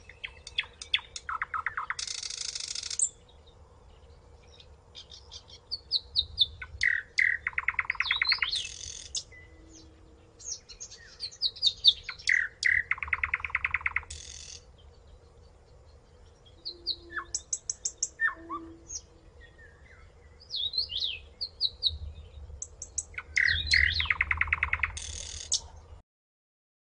夜莺歌唱声 新疆歌鸲鸟叫声